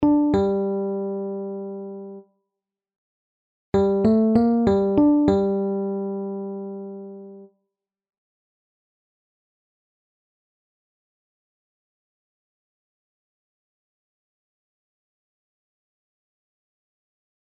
Kwint